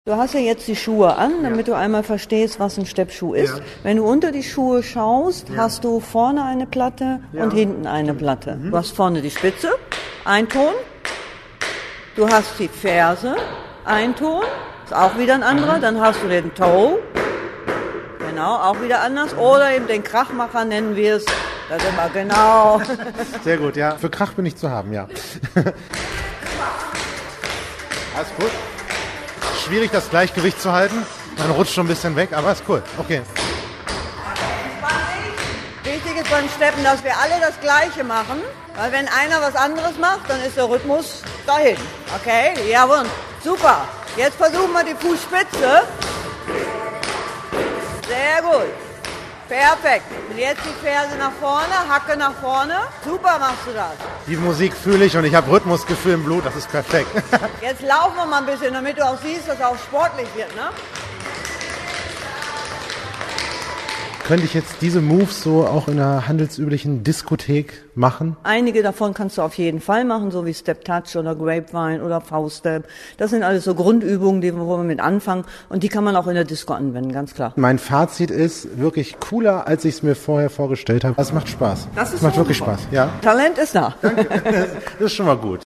hobby_stepptanzen.mp3